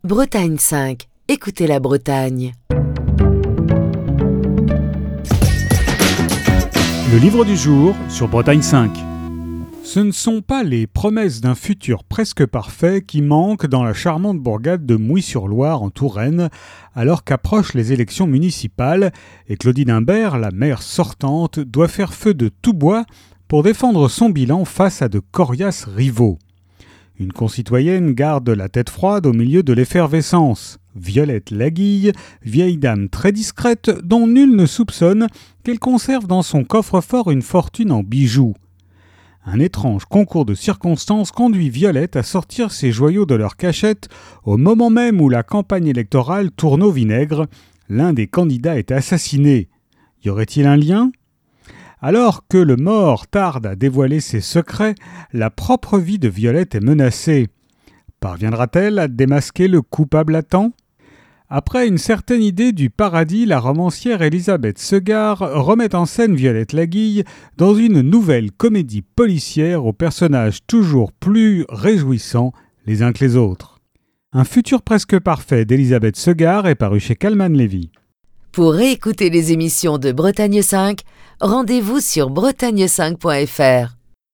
Chronique du 24 octobre 2022.